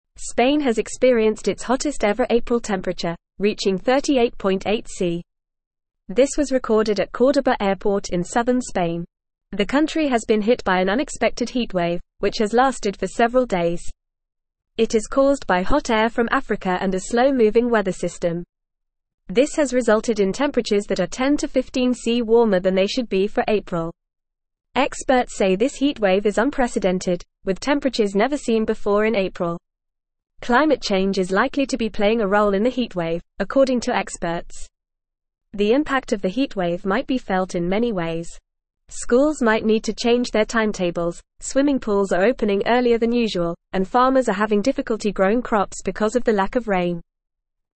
English-Newsroom-Beginner-FAST-Reading-Spain-Has-Hottest-April-Temperature-Ever.mp3